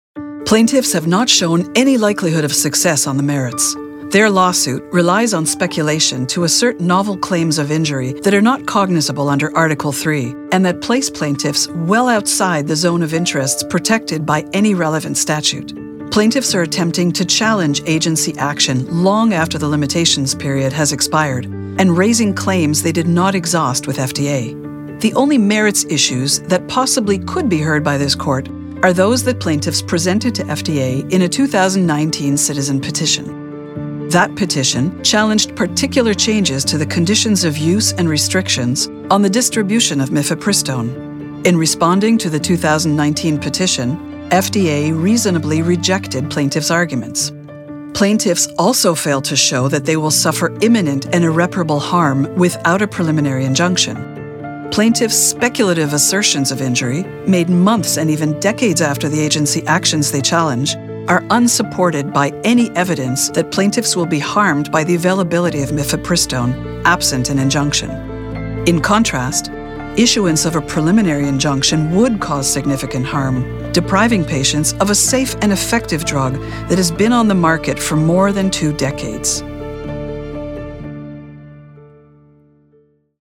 Montreal English / Canadian English
Velvety smooth, luxurious contralto.
Paired with a delivery that is professional and authoritative, the Doctor’s voice is sophisticated, clear, smooth and articulate.